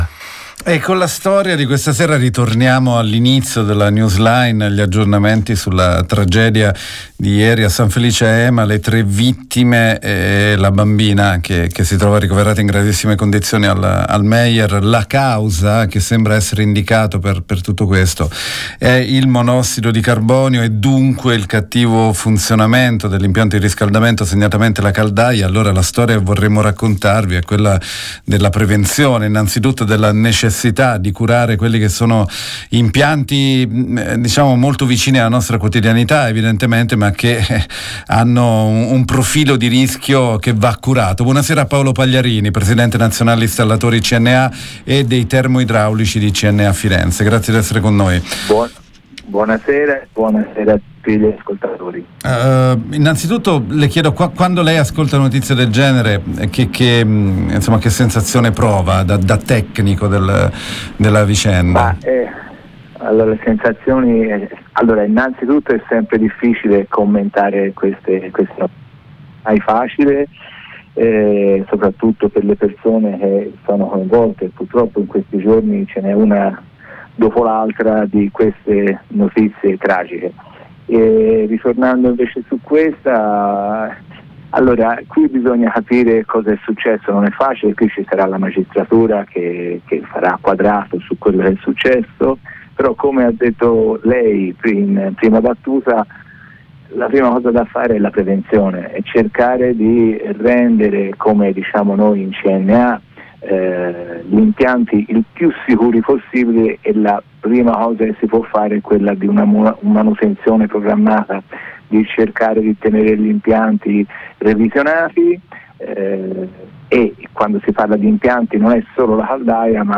Di fronte alle crescenti richieste di chiarimenti e preoccupazioni da parte dei cittadini, CNA Firenze interviene con una serie di consigli pratici e indispensabili per prevenire guasti e incidenti dagli esiti potenzialmente fatali. Intervista